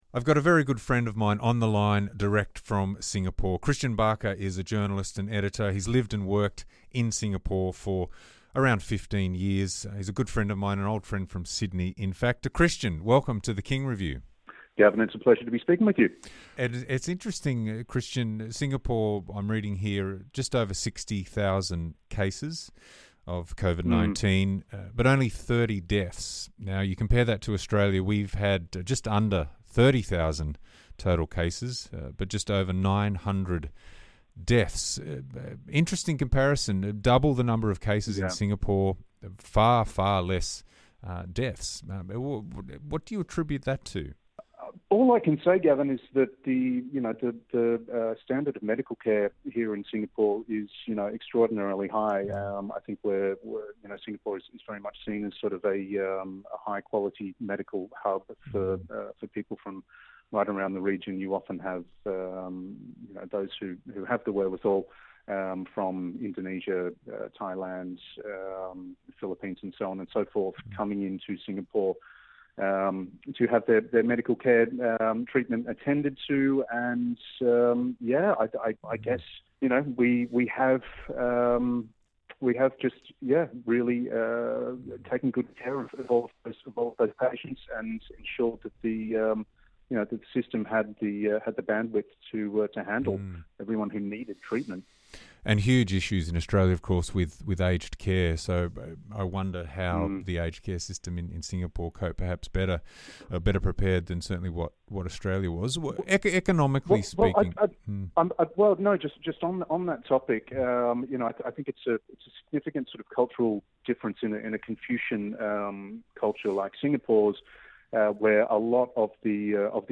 on the line from Singapore to talk travel bubbles and COVID-19